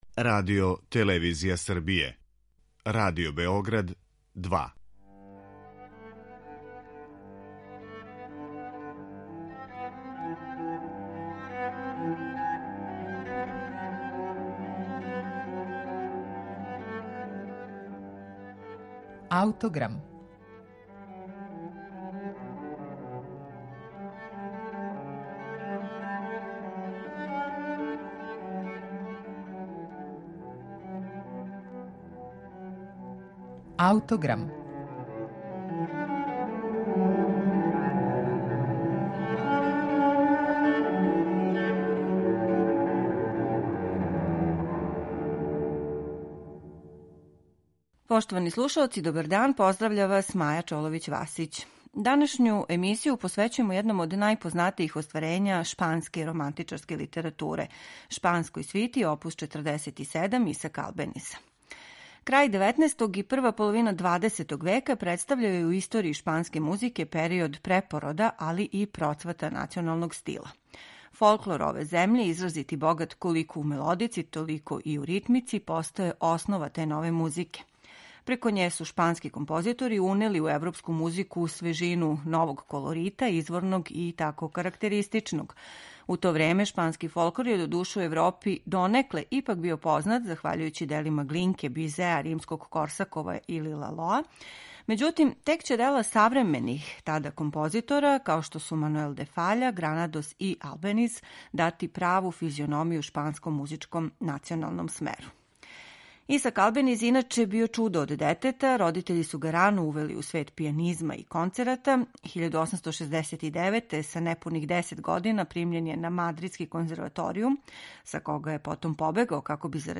Исак Албениз ‒ Шпанска свита за клавир